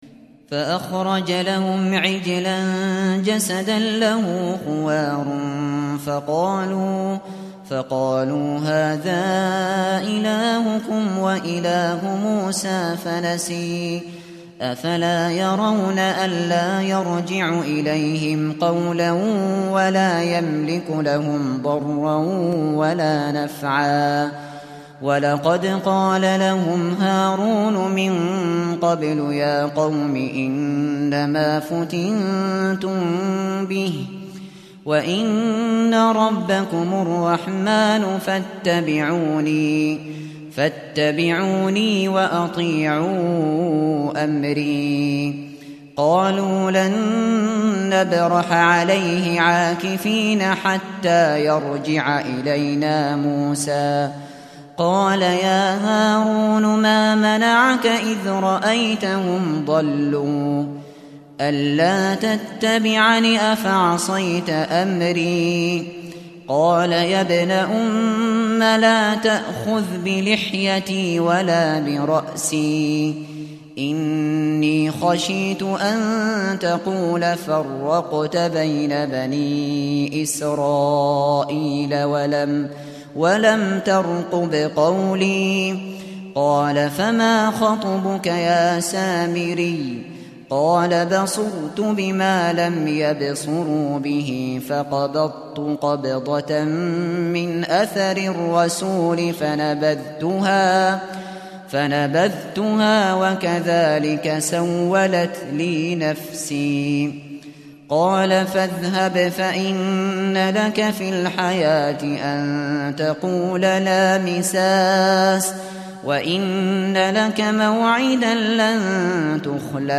Kur'ân dinlemeye başlamak için bir Hafız seçiniz.